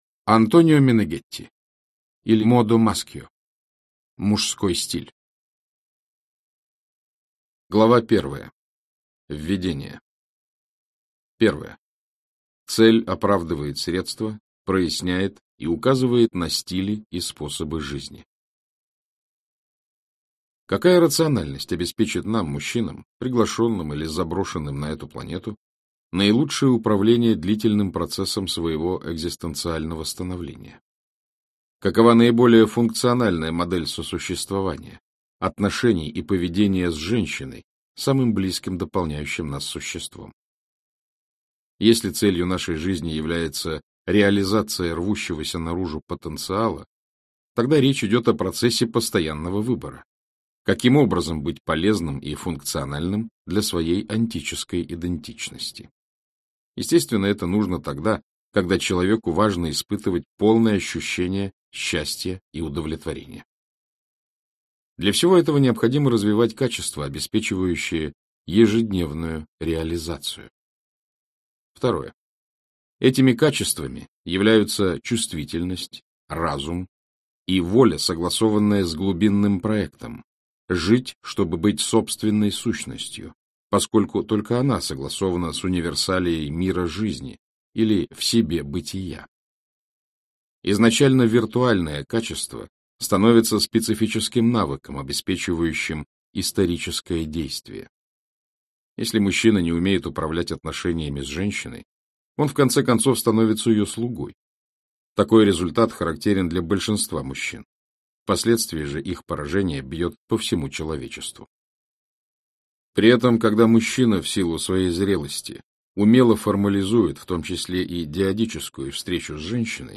Аудиокнига Мужской стиль в лидерстве и жизни | Библиотека аудиокниг